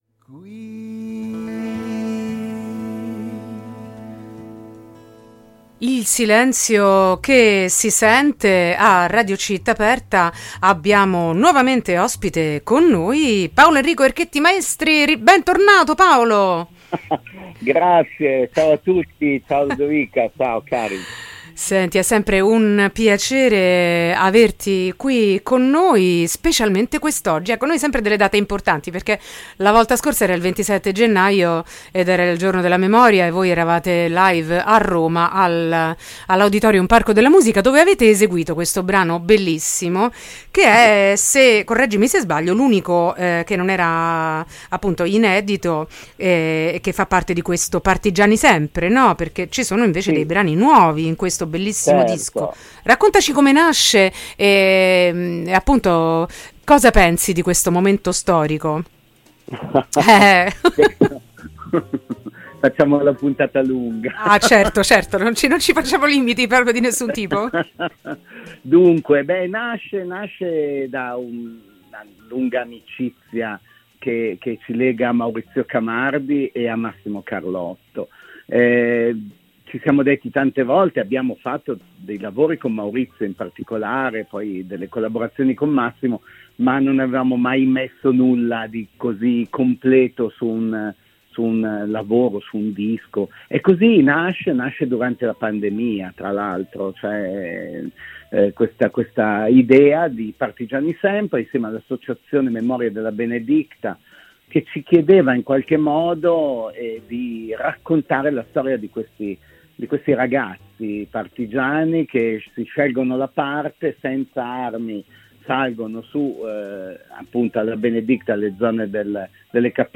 intervista-yoyomundi-partigiani-8-9-23.mp3